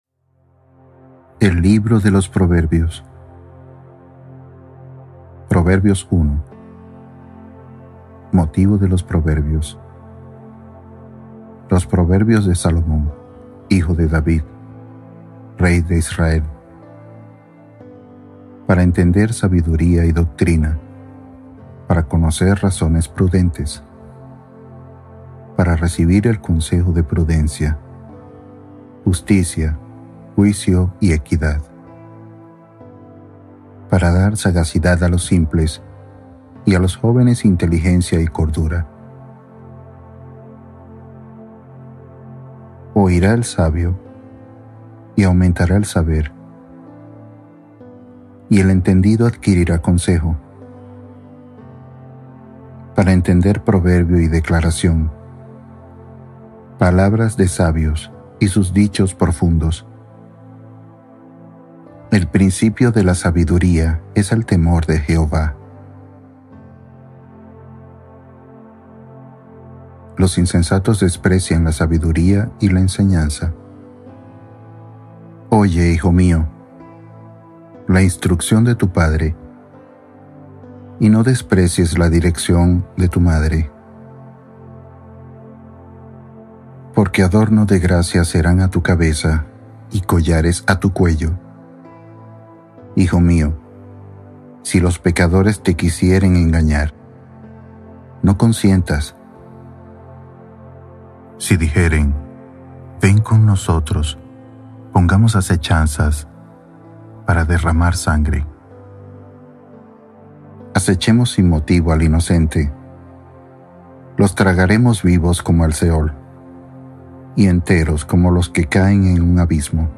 Palabra de Dios en audio | Biblia hablada 1960